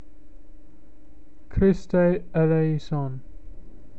Kris-   tay   ae-lay-ee-son.